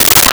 Water Drip 02
Water Drip 02.wav